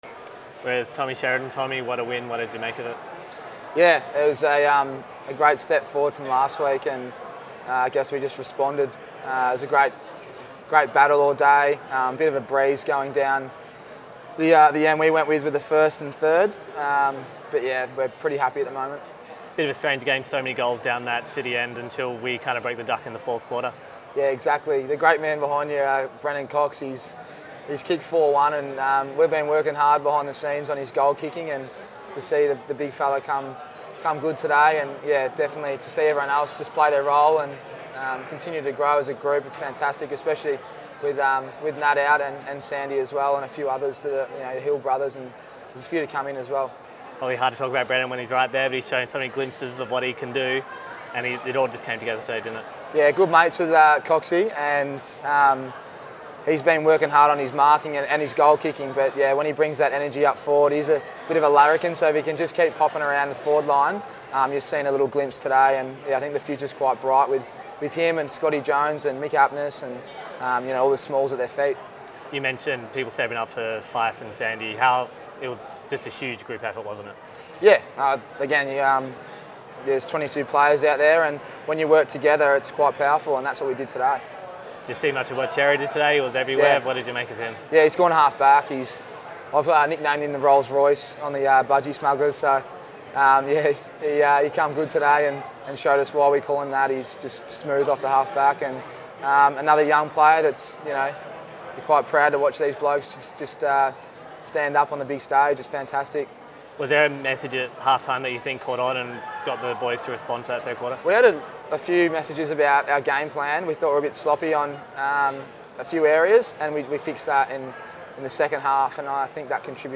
post-match interview